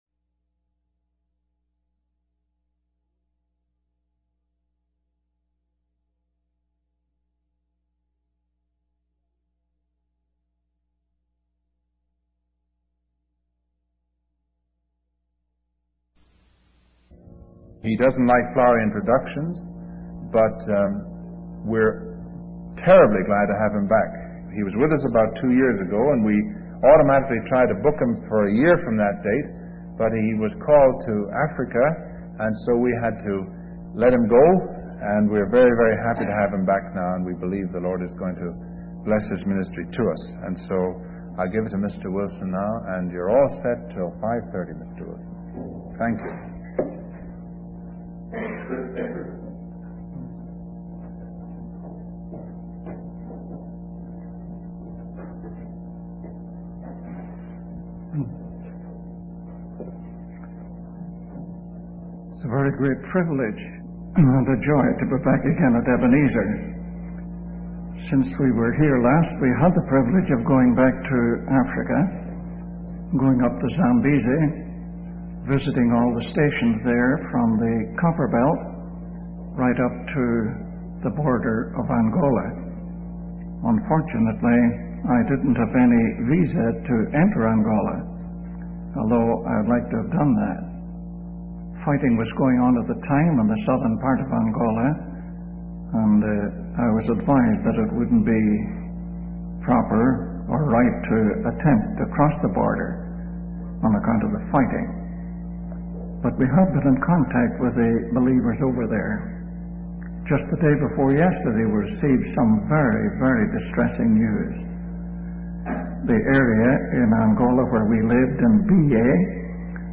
In this sermon, the preacher explains the structure of the Bible, comparing it to a book or a sermon.